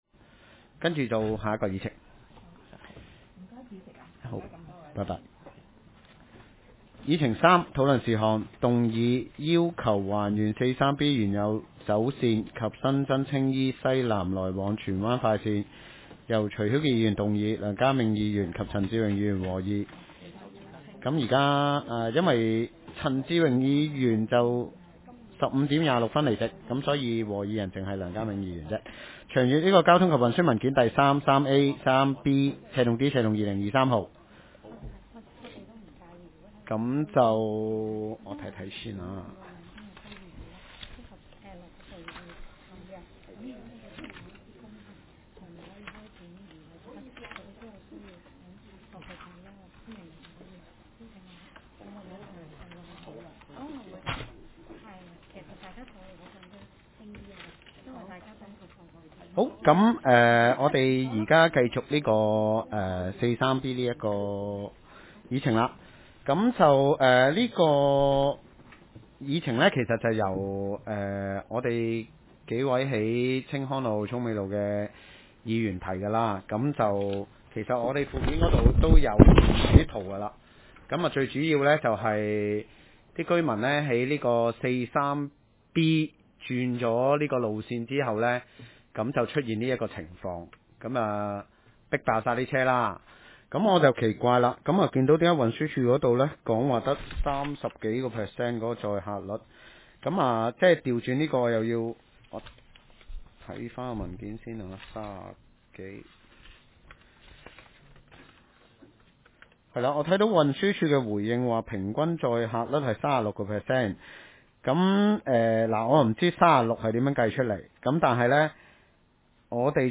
葵青區議員要求43B還原舊路線（議員在會議上撤回動議）
TTC_1st_Meeting_03.mp3